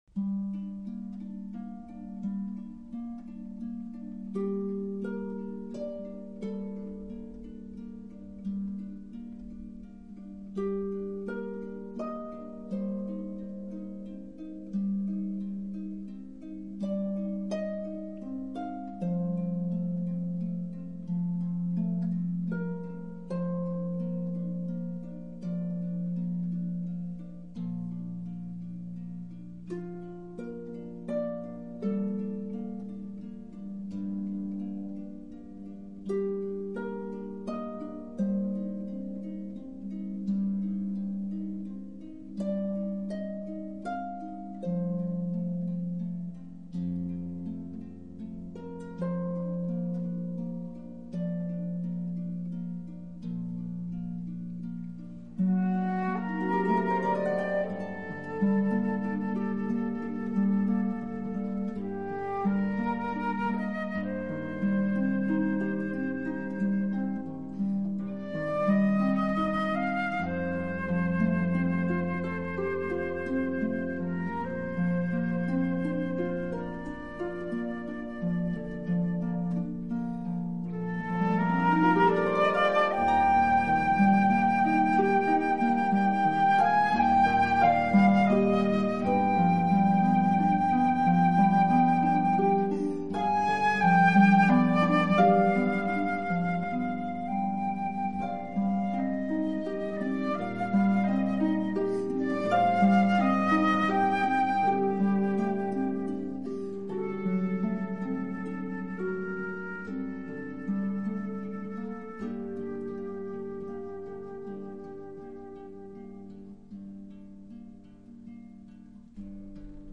harpist
flautist
" and "Nocturne" are romantic, yet gripping.